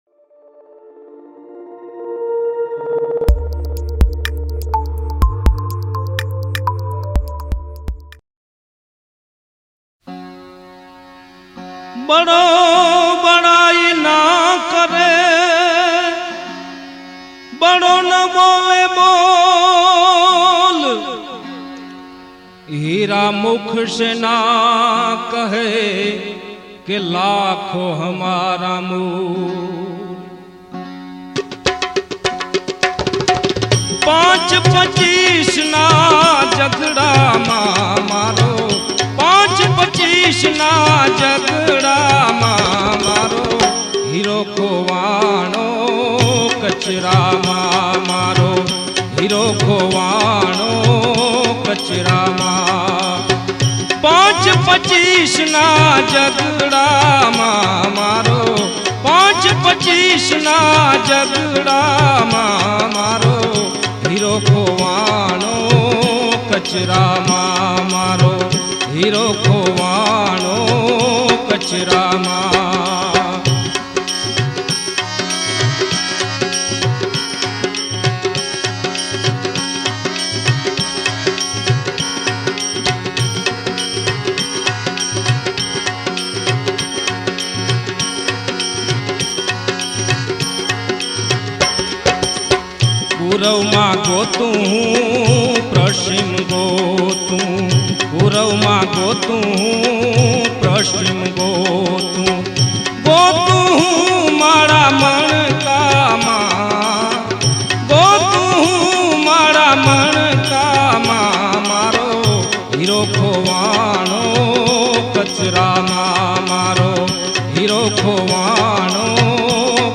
ગીત સંગીત ભજનાવલી - Bhajans
Desi Bhajan